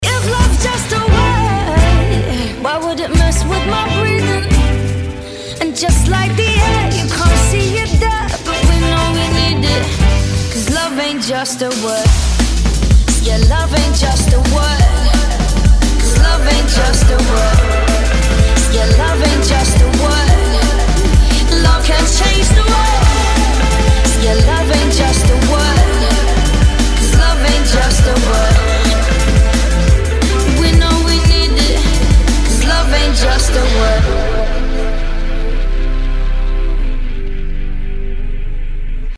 • Качество: 320, Stereo
drum&bass
Liquid
Забугорный Драм энд бэйс